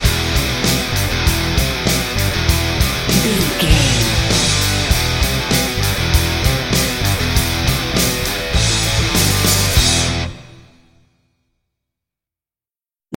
Aeolian/Minor
drums
electric guitar
Sports Rock
hard rock
lead guitar
bass
aggressive
energetic
intense
powerful
nu metal
alternative metal